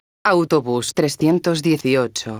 megafonias exteriores